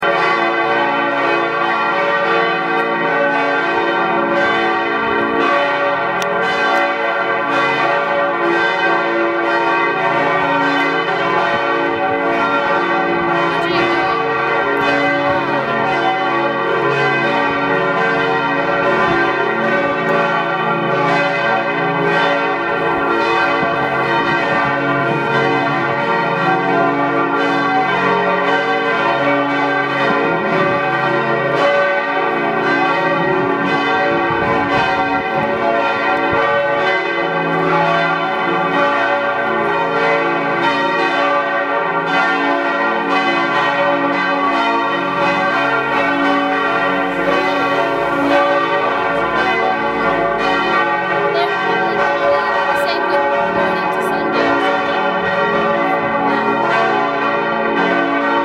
bells
bells.mp3